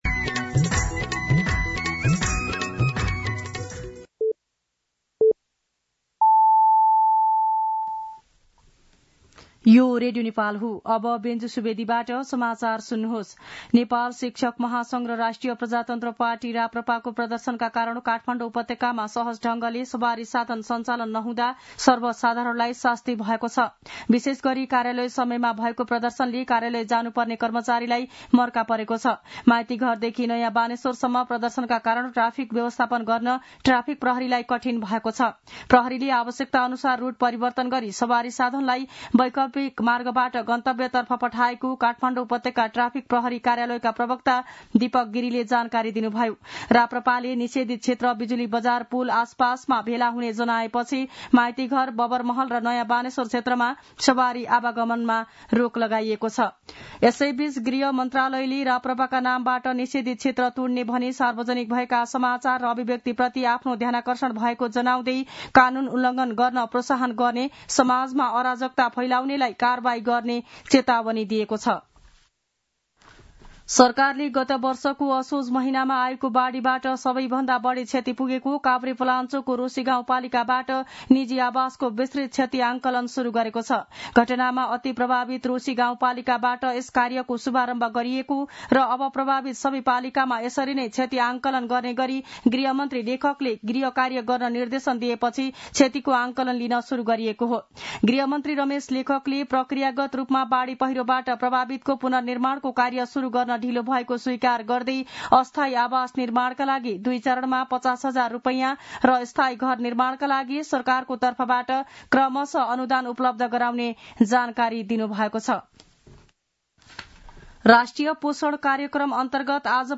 दिउँसो १ बजेको नेपाली समाचार : ७ वैशाख , २०८२
1-pm-Nepali-News-01-07.mp3